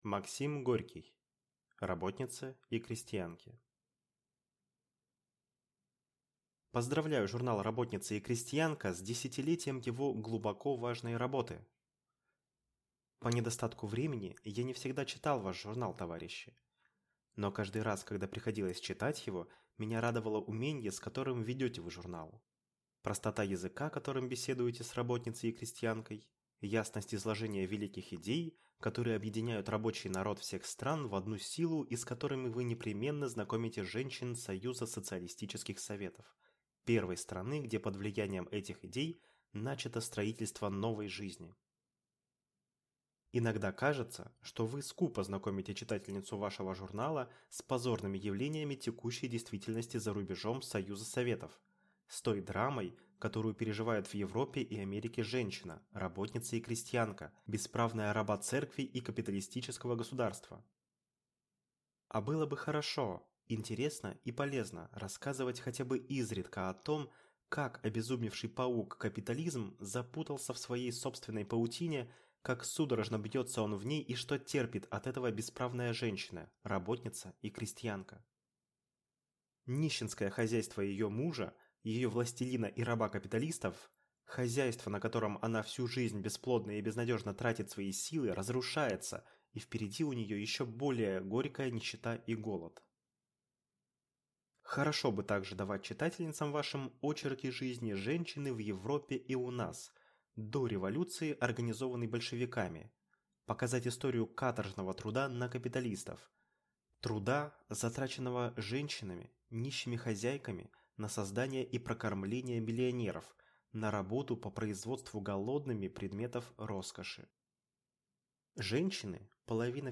Аудиокнига «Работнице и крестьянке» | Библиотека аудиокниг